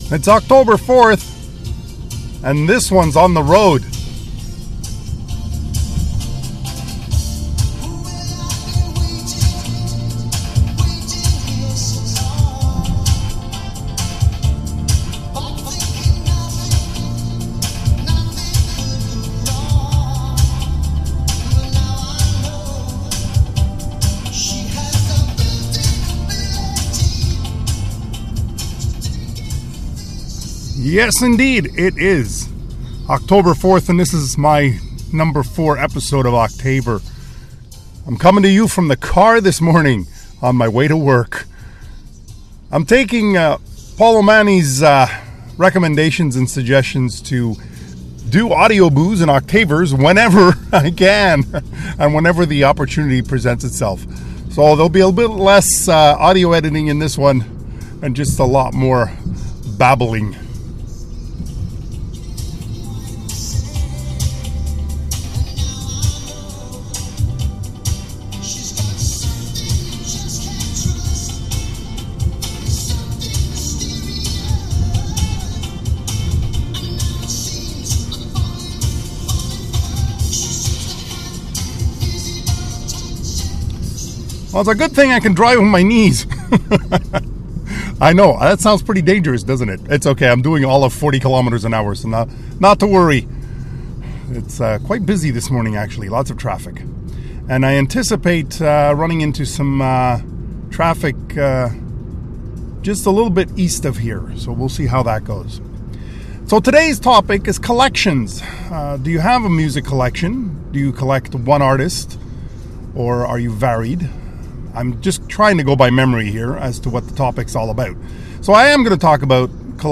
Sorry for the sound quality... :(